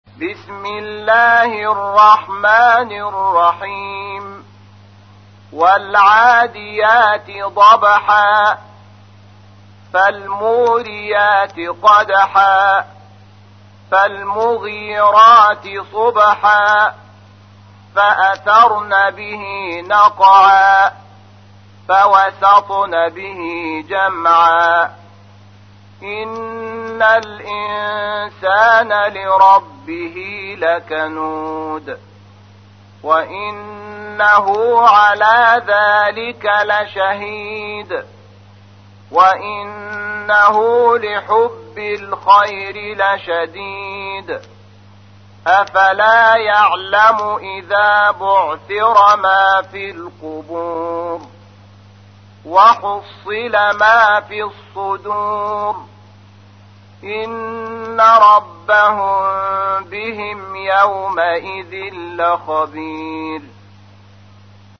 تحميل : 100. سورة العاديات / القارئ شحات محمد انور / القرآن الكريم / موقع يا حسين